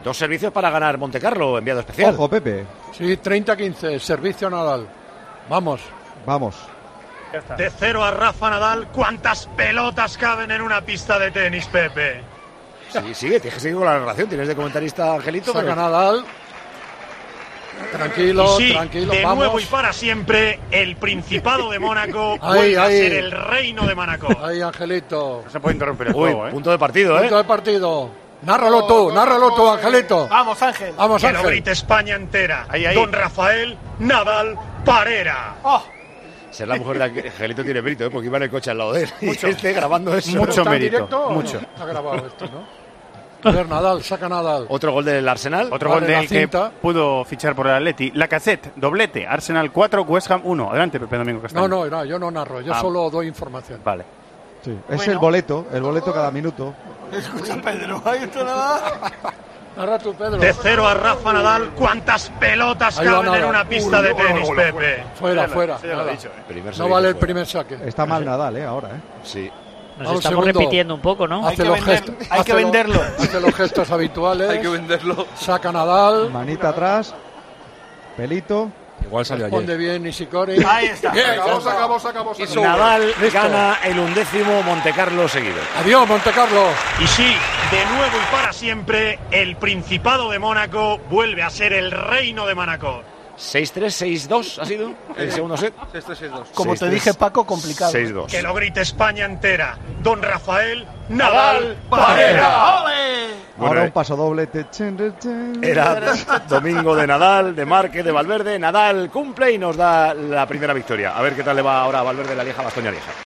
El enviado especial a Montecarlo, Pepe Domingo Castaño, narró así el punto que dio a Rafa Nadal su undécimo título en Montecarlo